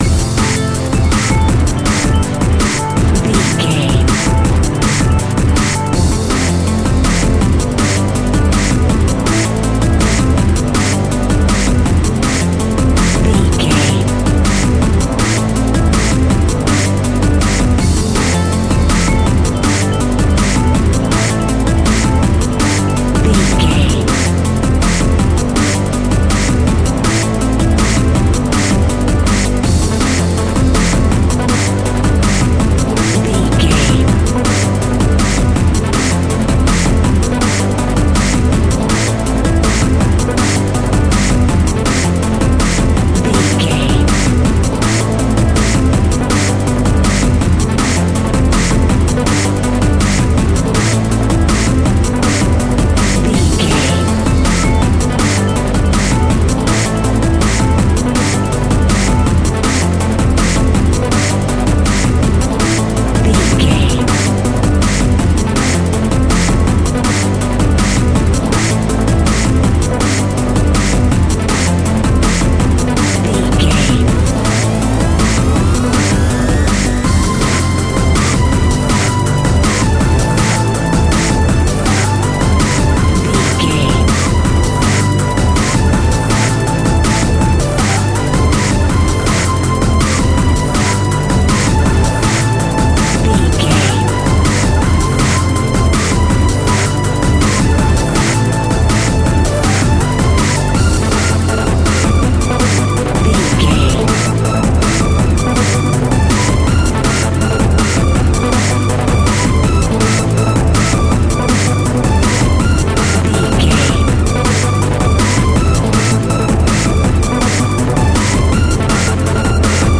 Aeolian/Minor
Fast
futuristic
frantic
driving
energetic
hypnotic
industrial
mechanical
powerful
drum machine
synthesiser
Drum and bass
instrumentals
synth bass
synth lead
synth pad
robotic